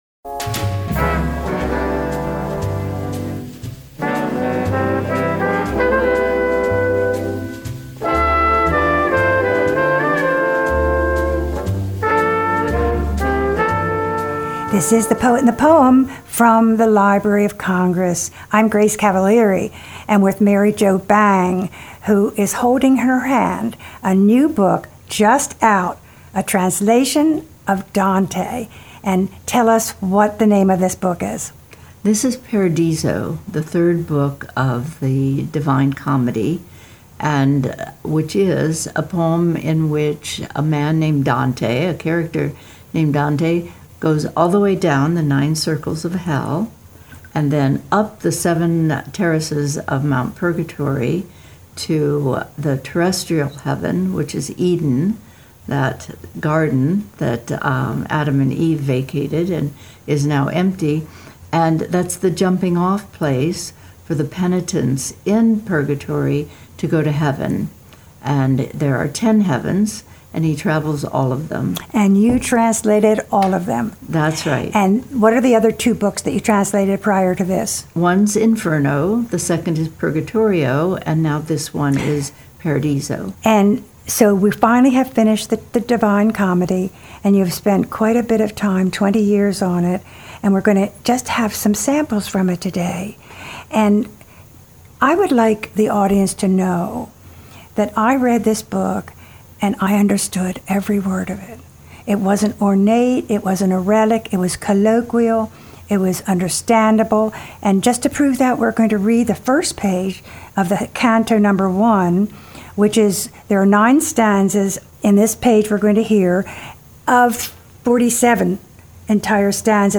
Interviews with U.S. Poets Laureate